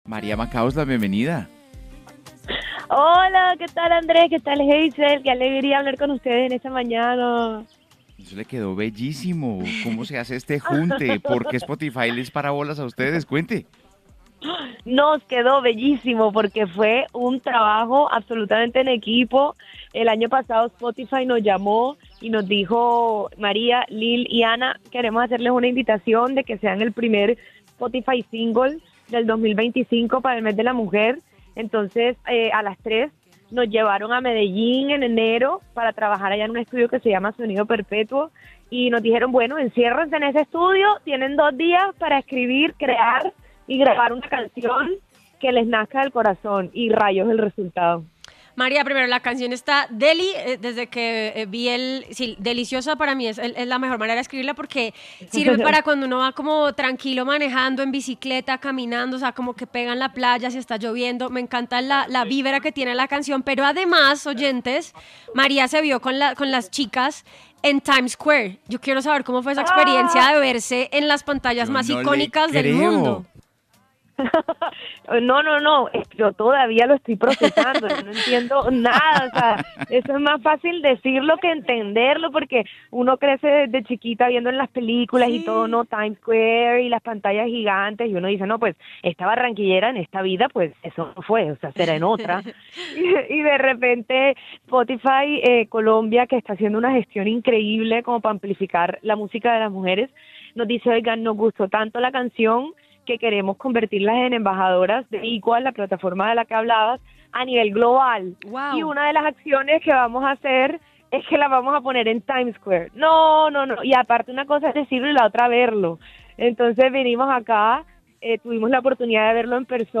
las talentosas artistas colombianas